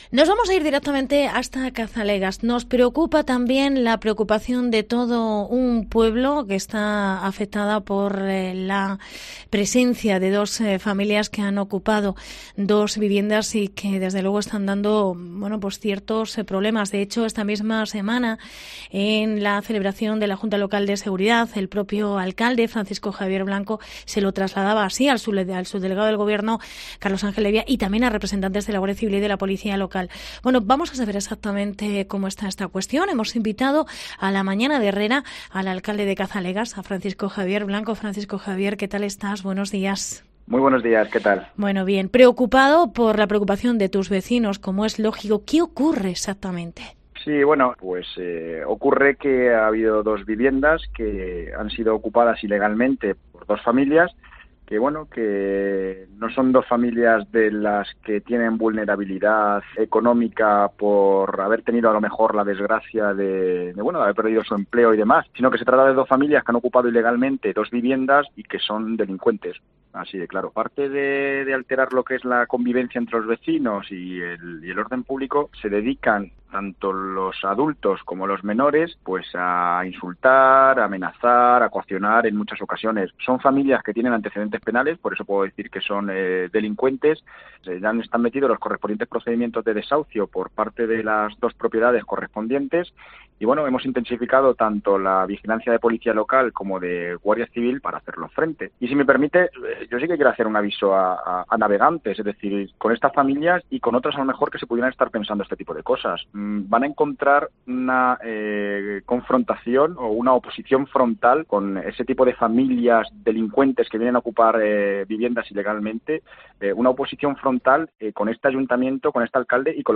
Entrevista Francisco Javier Blanco, alcalde de Cazalegas